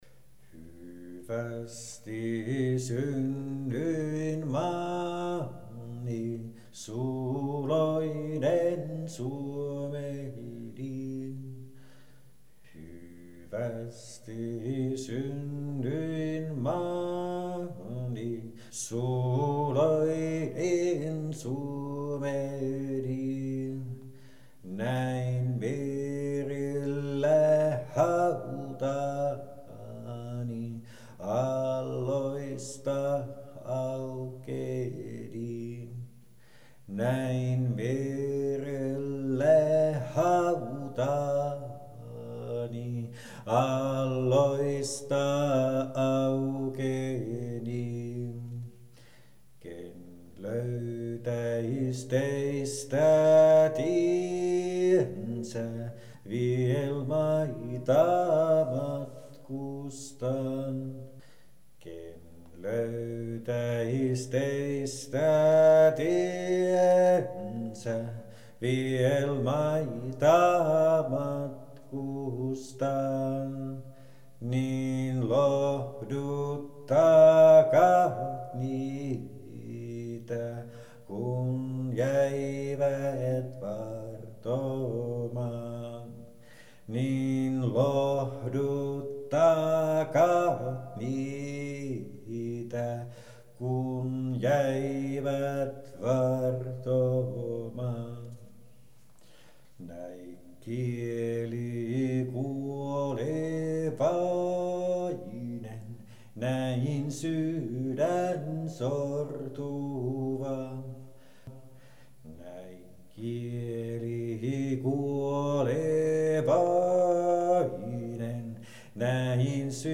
Tehtävät: Runot ja arkkiveisut
Kuuntele Frans August Kaseliuksen Murheweisu laulettuna.